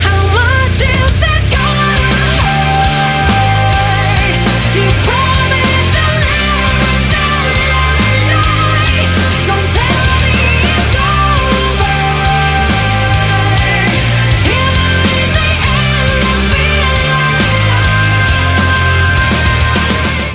Мощная песня с красивым женским вокалом